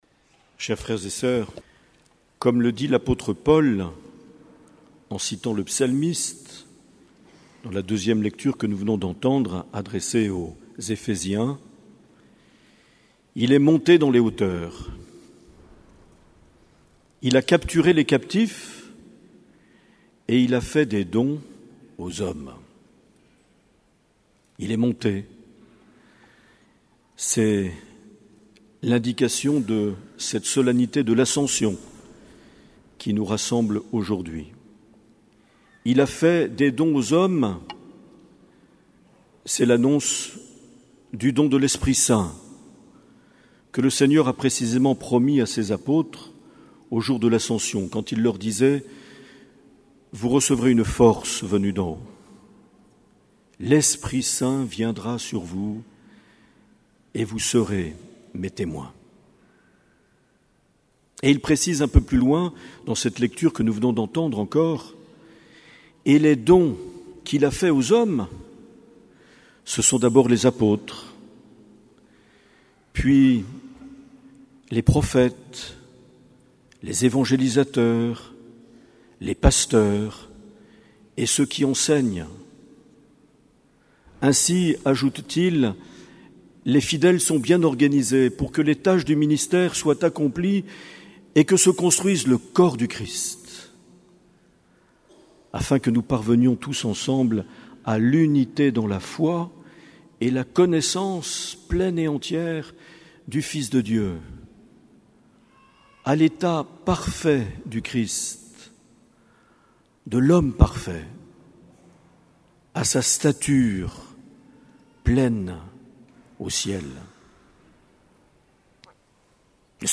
10 mai 2018 - Cathédrale de Bayonne - Ordinations diaconales
Les Homélies
Une émission présentée par Monseigneur Marc Aillet